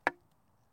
sfx_hit_ground.mp3